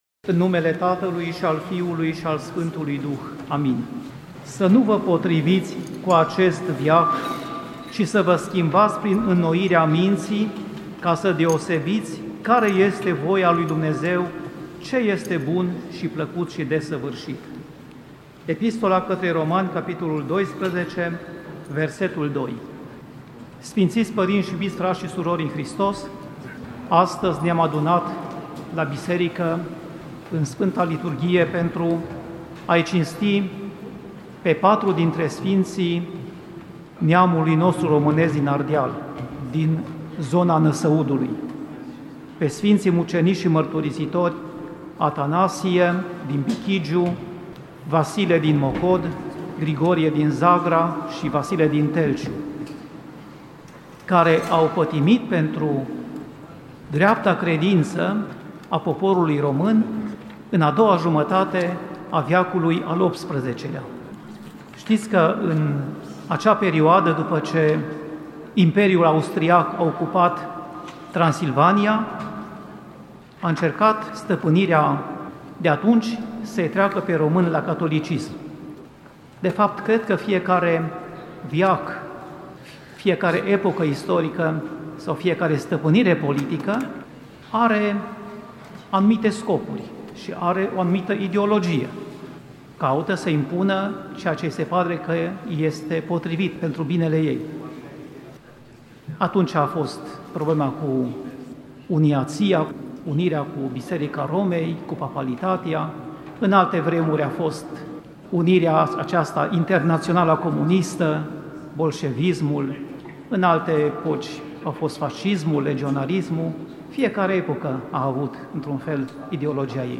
Predică la Sărbătoarea Sfinților Martiri Năsăudeni